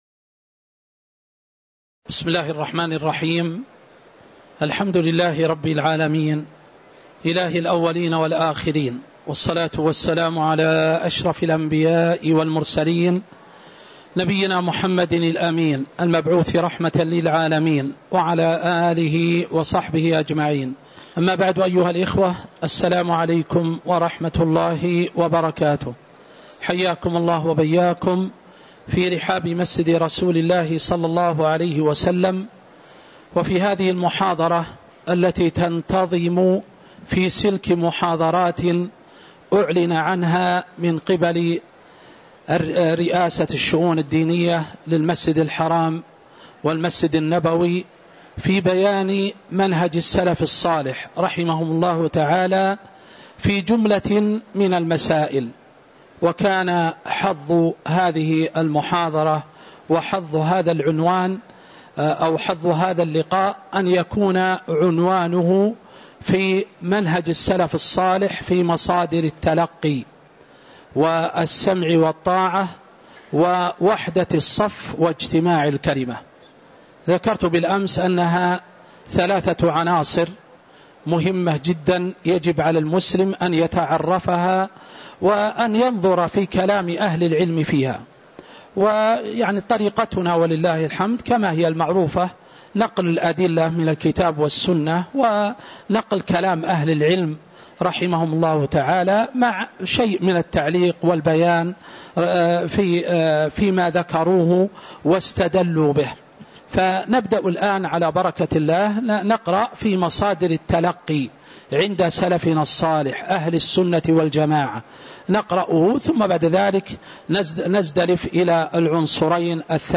تاريخ النشر ٨ جمادى الآخرة ١٤٤٦ هـ المكان: المسجد النبوي الشيخ